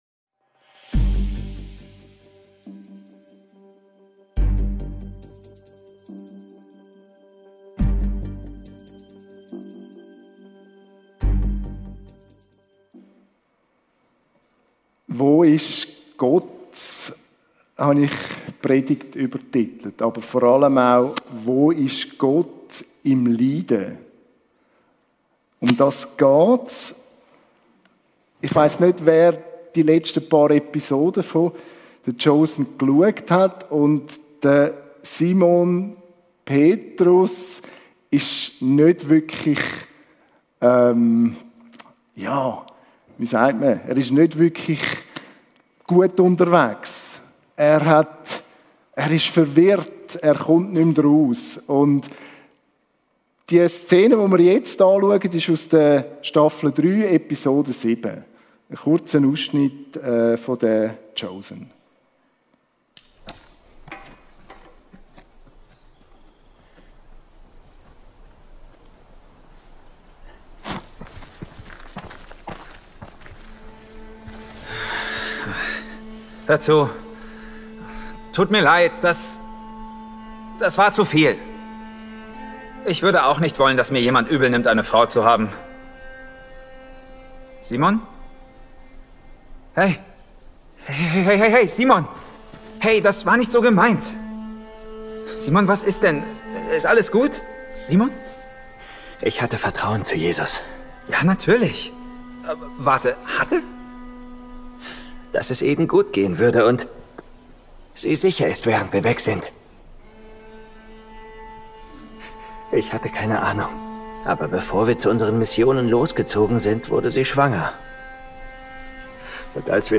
Predigt-Podcast
Audio-Predigten der Kirche Wigarten / Fällanden (CH)